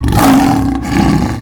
lion1.ogg